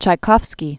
(chī-kôfskē), Peter Ilich 1840-1893.